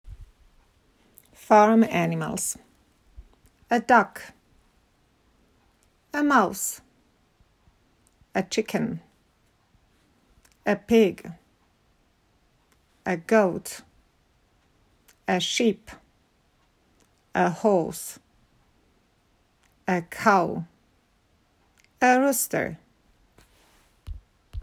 Powtórzysz ze mną? Kliknij play▶, patrz na karty obrazkowe i powtarzaj: